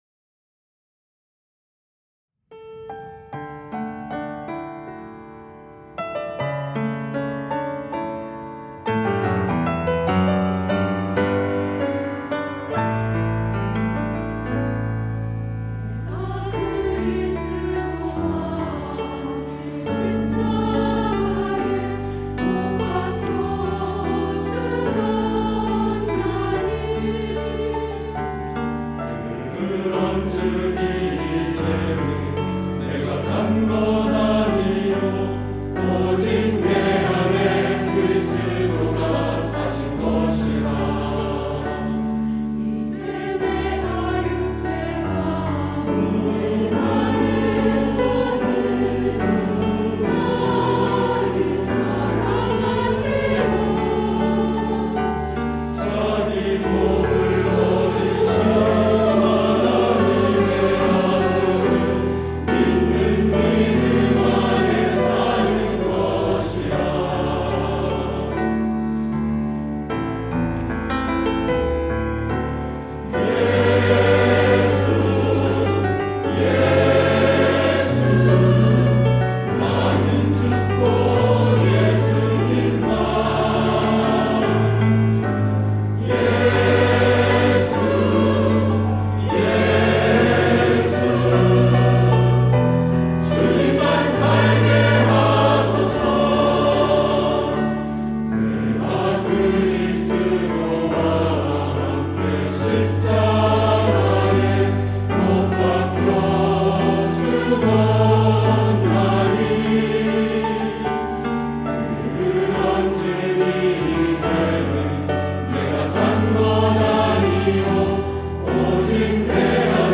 할렐루야(주일2부) - 나는 죽고 내 안에 그리스도만
찬양대